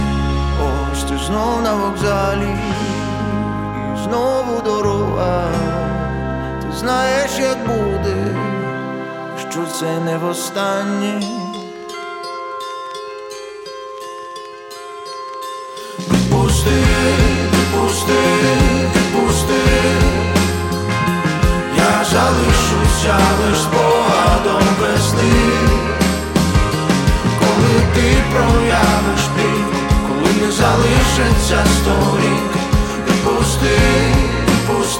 Жанр: Поп / Инди / Украинские
# Indie Pop